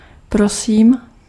Ääntäminen
Tuntematon aksentti: IPA: /de.zɔ.le/